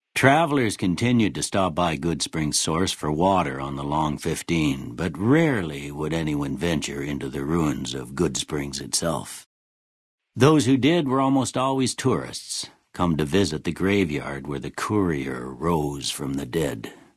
Category:Fallout: New Vegas endgame narrations Du kannst diese Datei nicht überschreiben.